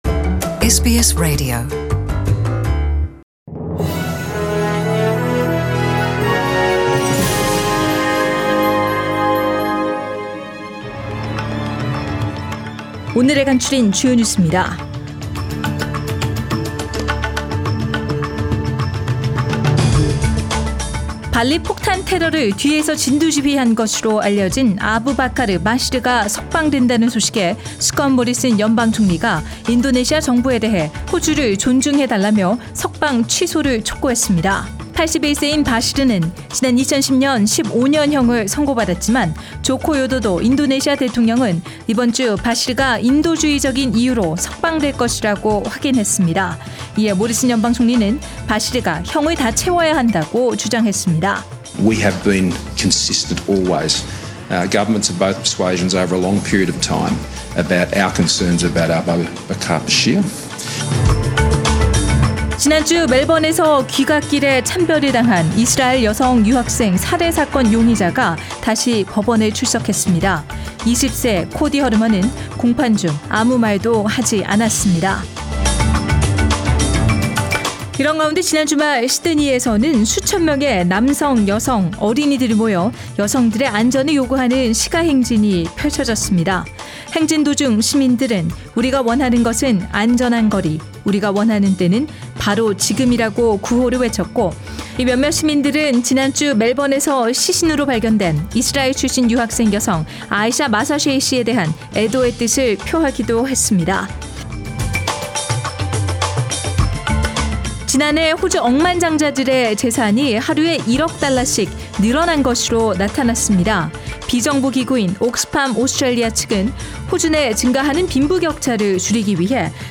SBS Radio Korean News Bulletin Source: SBS Korean program